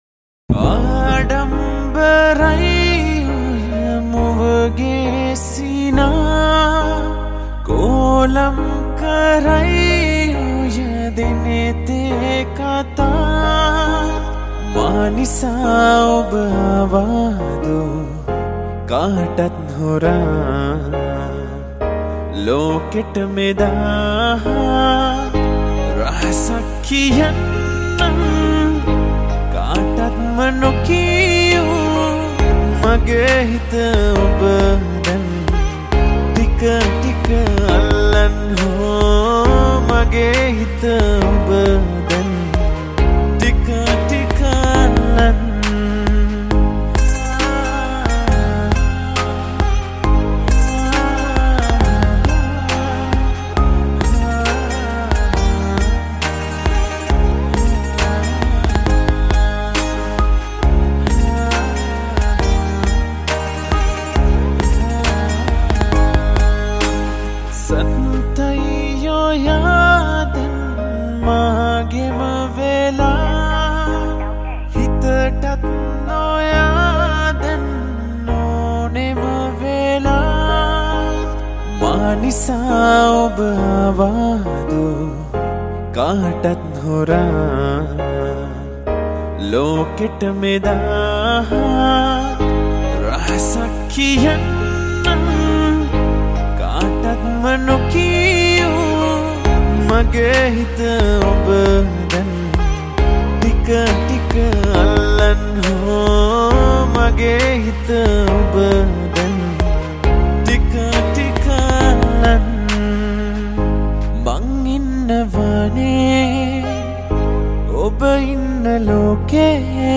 Sinhala Cover Version